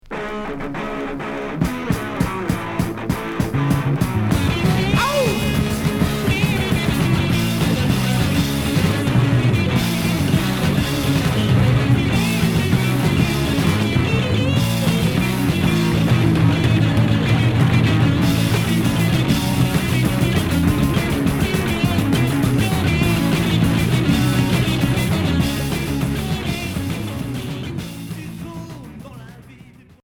Punk rock Hard Unique 45t retour à l'accueil